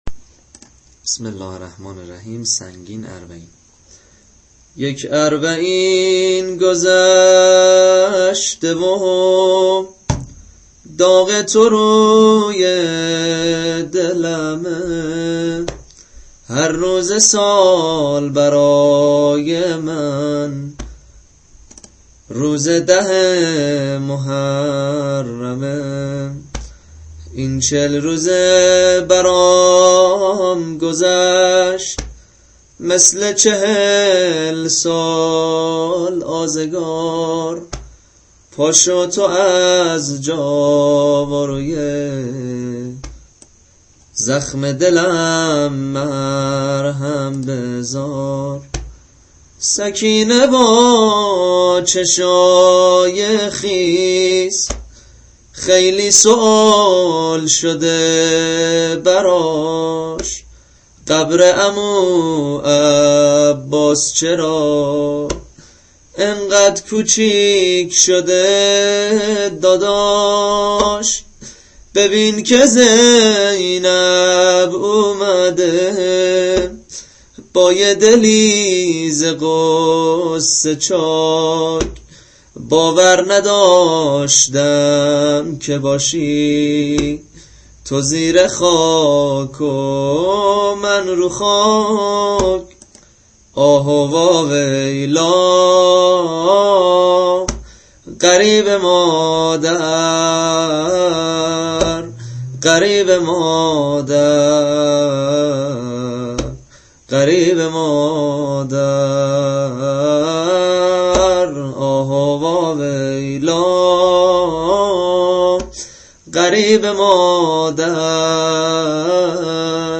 نوحه همراه با سبک